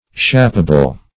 Shapable \Shap"a*ble\, a.